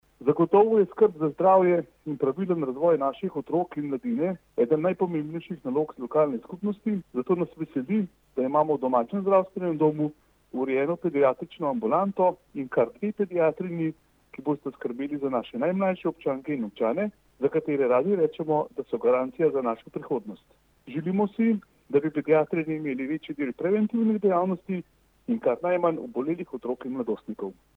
izjava_dusanbodlajpodzupanobcinetrzicvzacasnemopravljanjufunkcijezupana.mp3 (672kB)